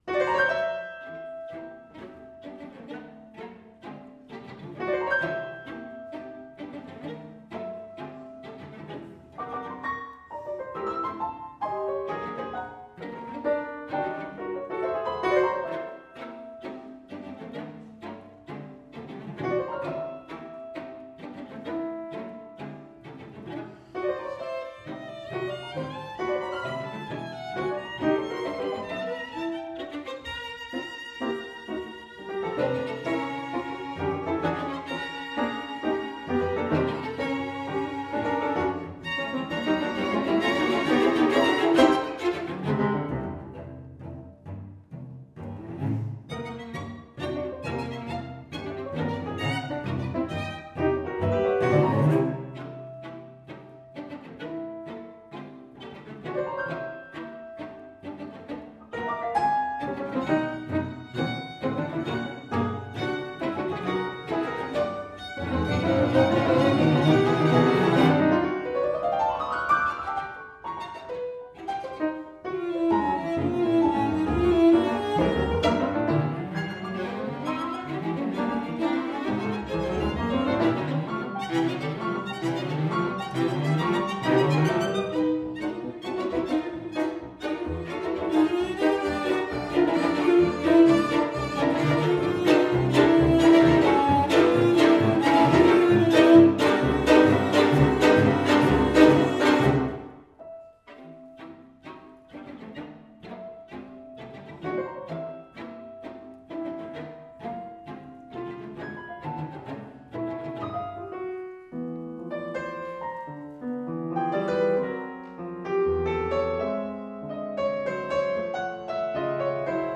[strings]
cello
piano) Sextet - Deel II